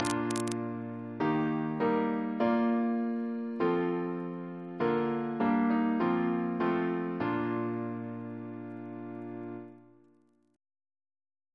CCP: Chant sampler
Single chant in B♭ Composer: George A. Macfarren (1813-1887) Reference psalters: ACB: 85; CWP: 185; RSCM: 194